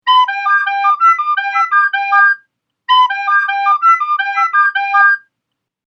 Wer noch keinen Einsatzwerk für die neue Funktion hat, der lädt sich diese Marimba-Interpretation von unserer Webseite.
marimba_blockfloete.mp3